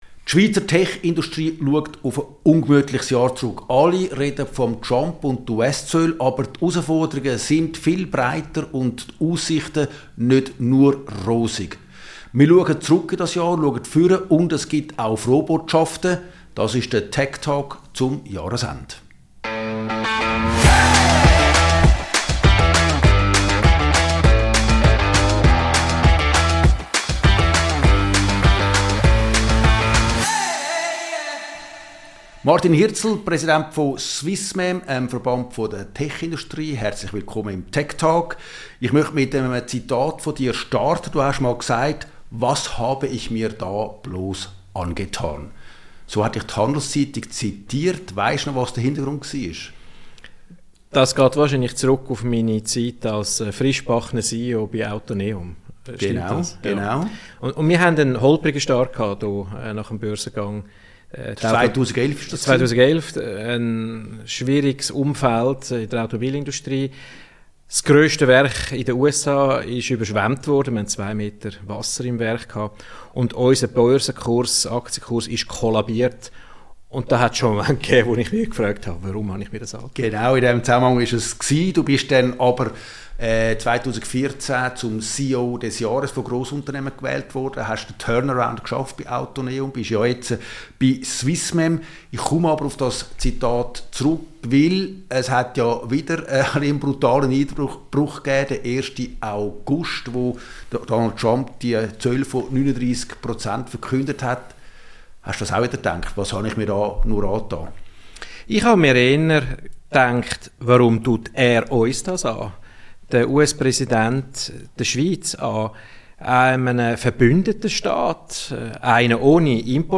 Beschreibung vor 4 Monaten US-Zölle, Frankenstärke, Probleme in Deutschland und zunehmende Bürokratie setzen der Schweizer Tech-Industrie zu. Im Gespräch